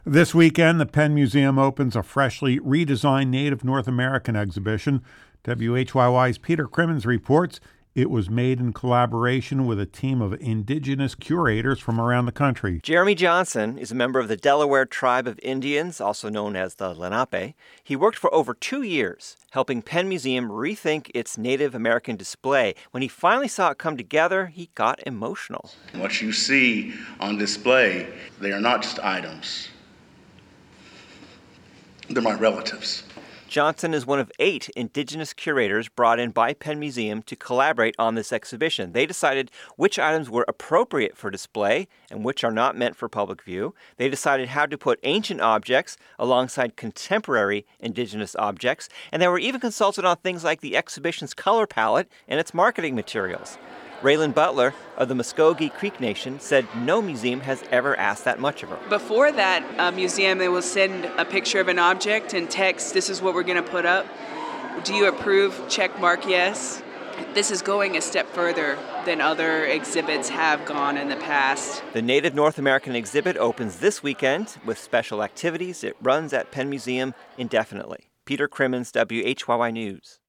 Philadelphia rang in 2017 with fireworks over the Delaware River.